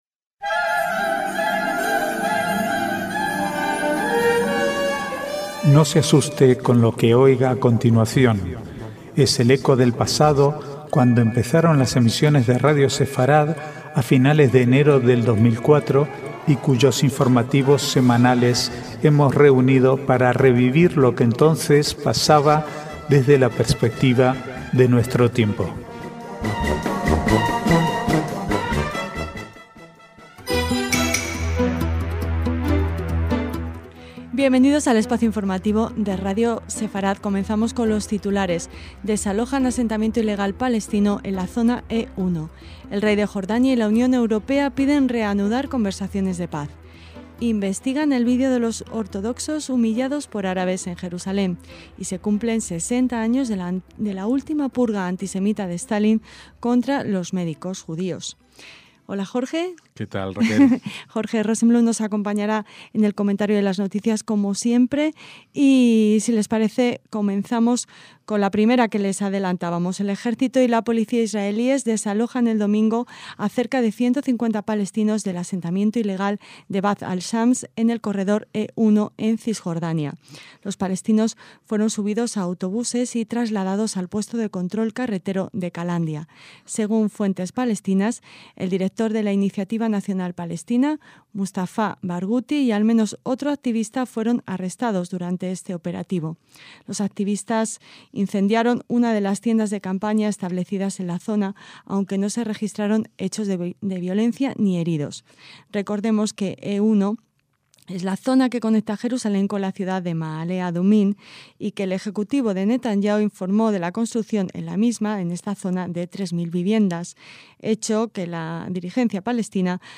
Archivo de noticias del 15 al 18/1/2013